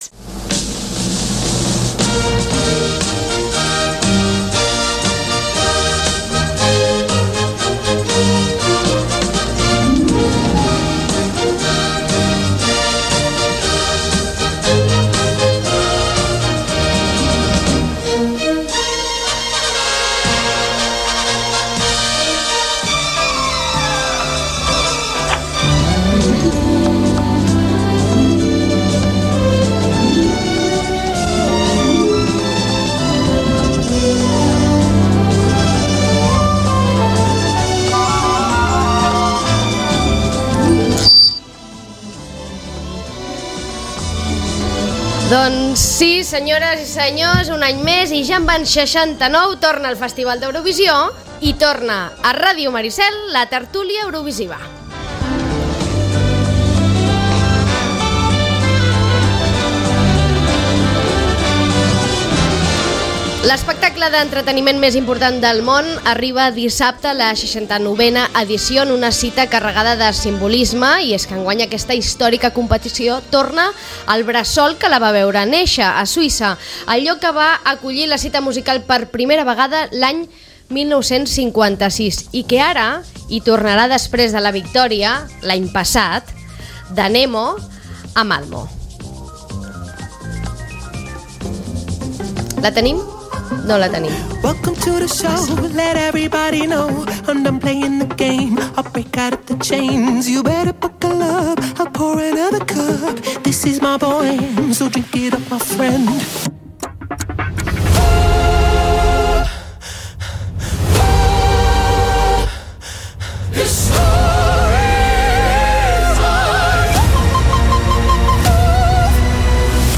Ràdio Maricel. Emissora municipal de Sitges. 107.8FM. Escolta Sitges.